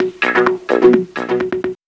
VTDS2 Song Kit 10 Rap 1 Out Of 2 Fill In.wav